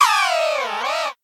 Catégorie:Cri Pokémon (Soleil et Lune) Catégorie:Cri de Dodoala